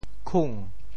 How to say the words 菌 in Teochew？
TeoChew Phonetic TeoThew kung2